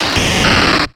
Cri de Drascore dans Pokémon X et Y.